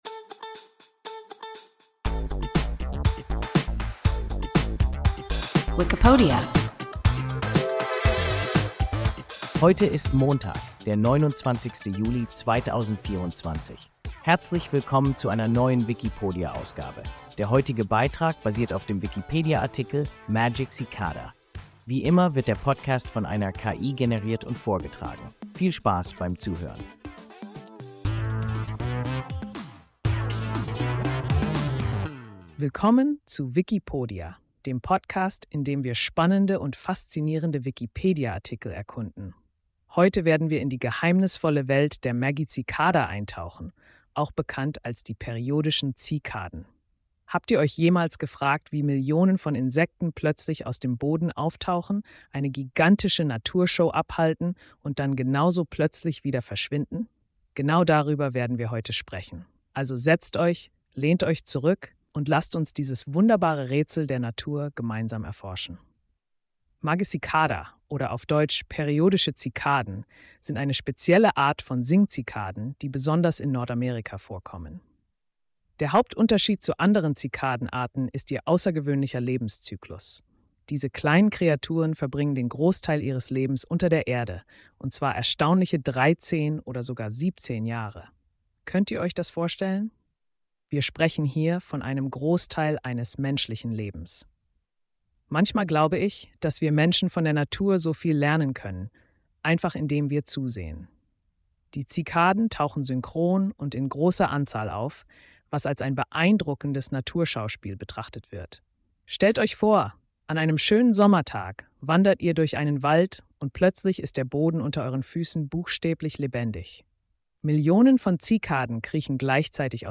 Magicicada – WIKIPODIA – ein KI Podcast